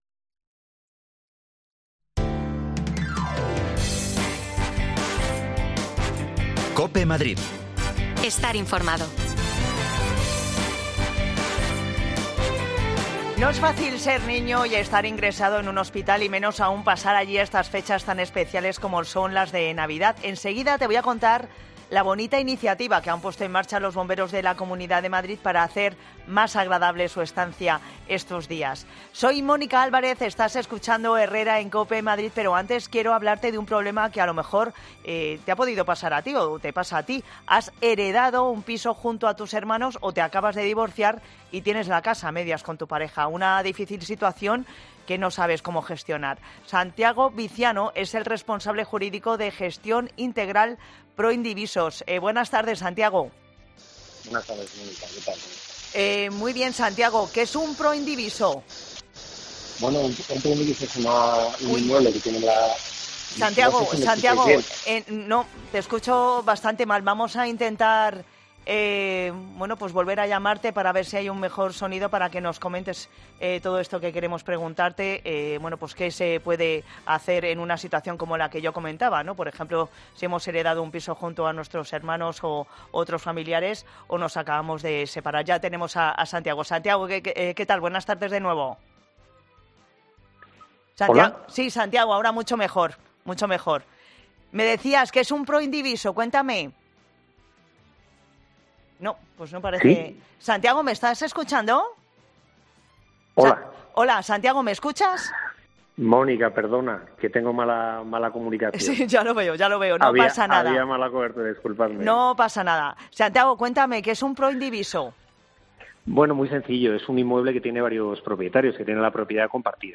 Los niños ingresados en el Hospital Puerta de Hierro han recibido hoy una visita muy especial... Te lo contamos desde allí
Las desconexiones locales de Madrid son espacios de 10 minutos de duración que se emiten en COPE, de lunes a viernes.